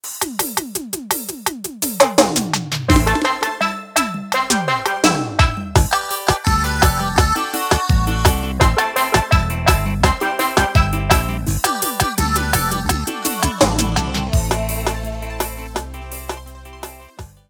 • Demonstrativo Pisadinha: